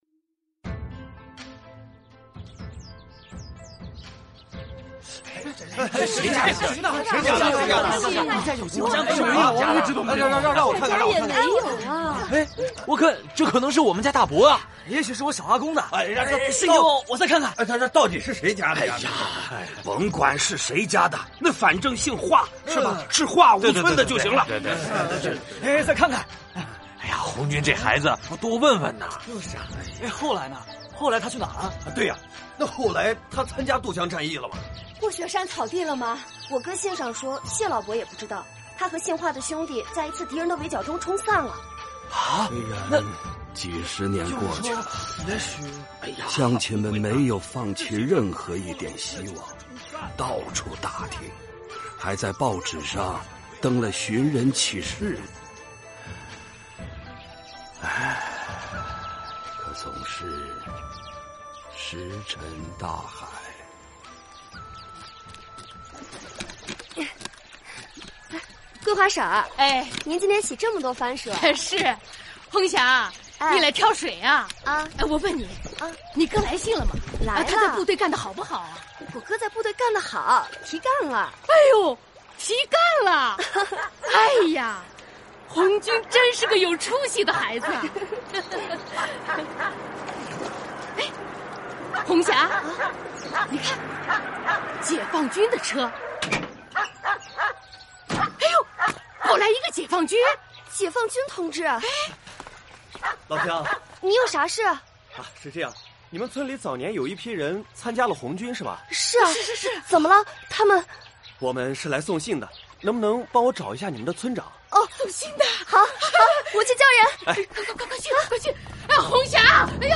广播剧《信念树》是以中央苏区瑞金市叶坪乡华屋村“17棵松”的故事为原型，进行艺术加工创作的革命历史题材广播剧。苏区时期，仅43户村民的华屋村有17名青壮年男子报名参加红军，他们最小的13岁，最大的也仅20来岁。